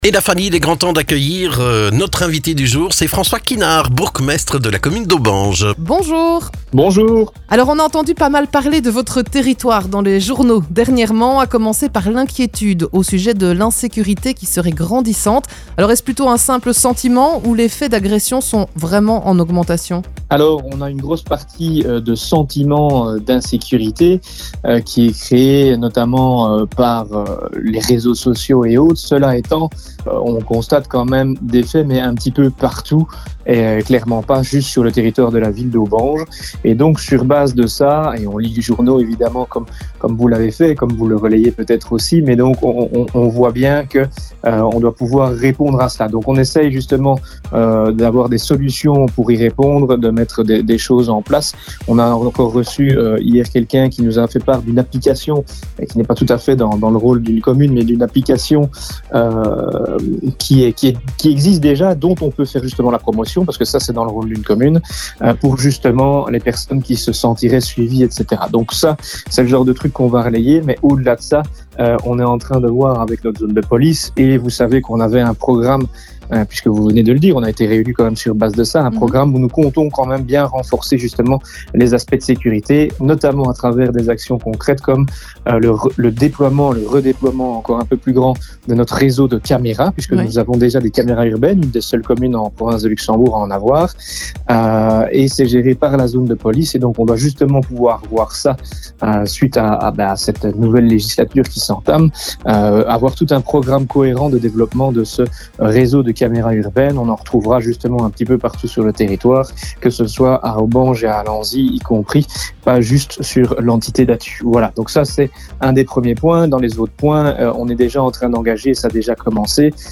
Le bourgmestre François Kinart revient sur les dossiers importants de la commune d'Aubange : l'insécurité, la mobilité avec l'arrêt potentiel de la gare d'Aubange et les travaux qui vont impacter le centre ville cette année...